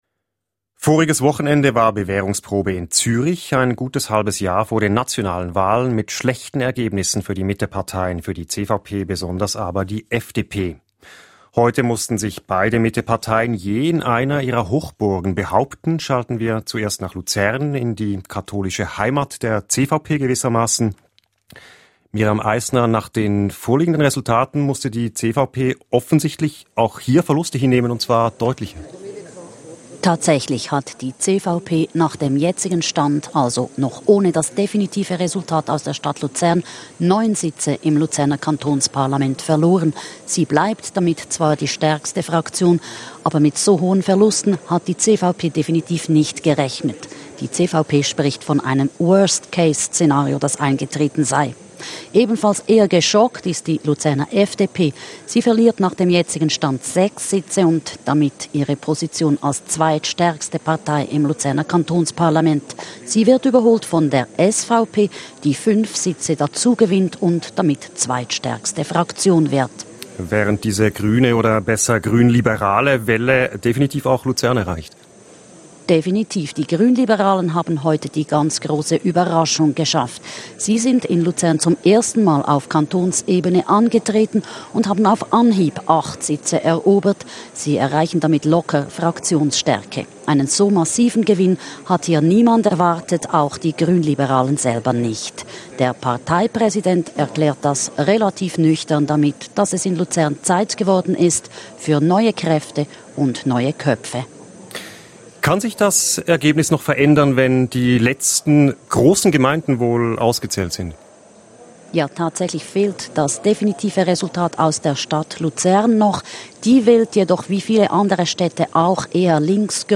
Dazu ein Gespräch